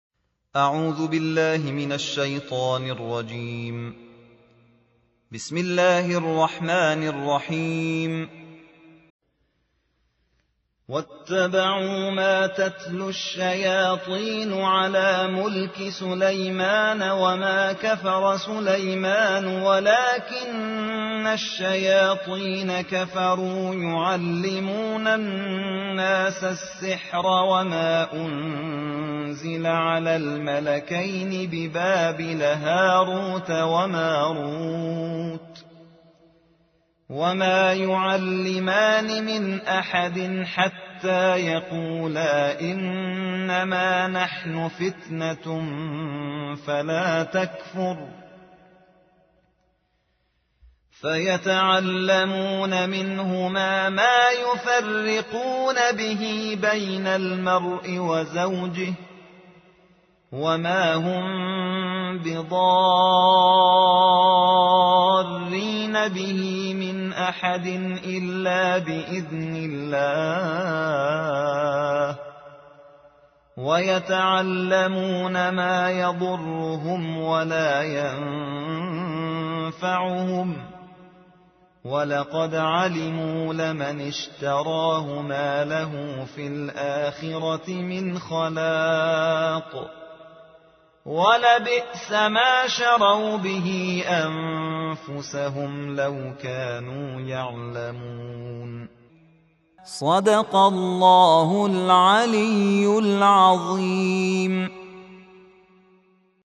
قرائت شبانه